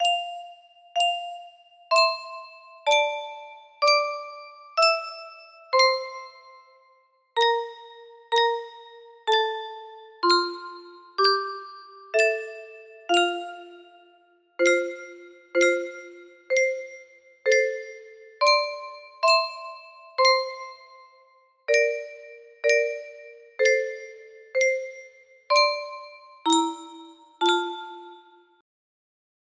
Brockington's Offer music box melody